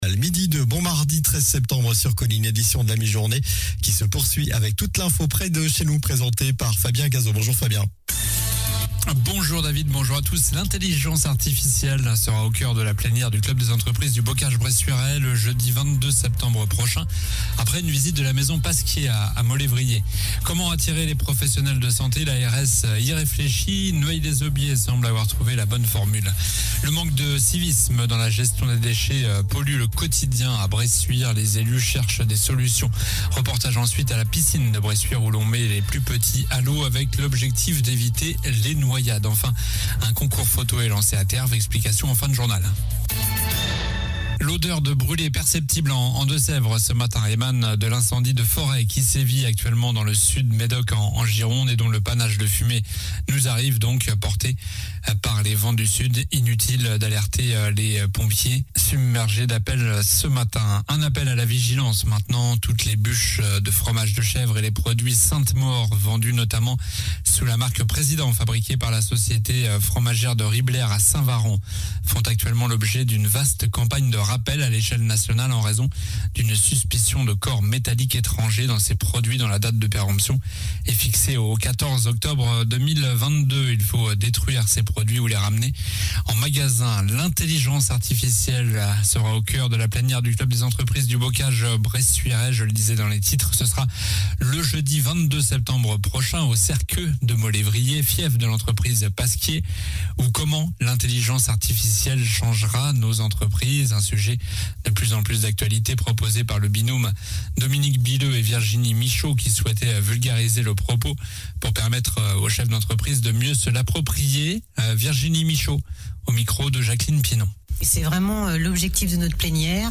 Journal du mardi 13 septembre (midi)